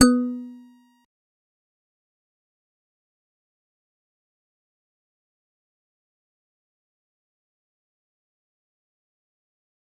G_Musicbox-B3-pp.wav